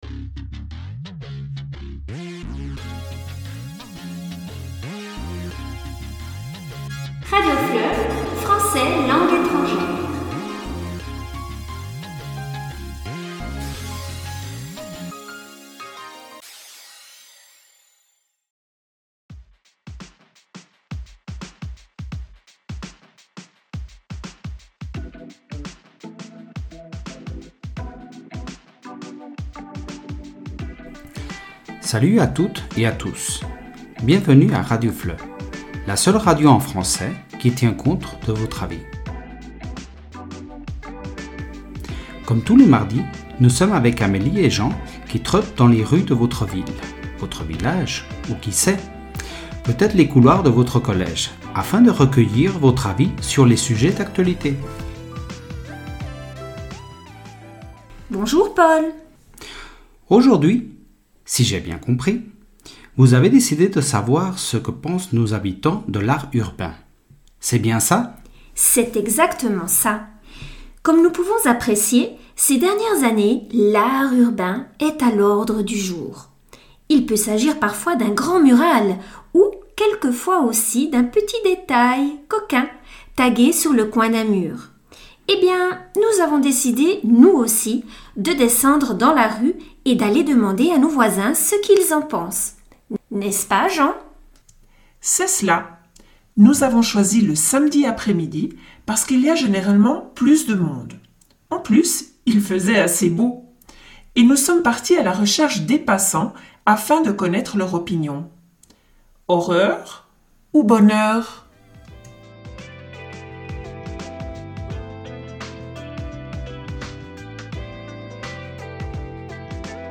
Évaluable - MICRO-TROTTOIR | 3ème ESO - FRANÇAIS - 2ème Langue Étrangère
MP3_-_JINGLE__and__MICROTROTTOIR_Art_urbain.mp3